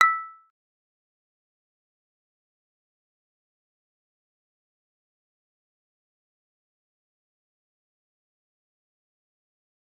G_Kalimba-E7-mf.wav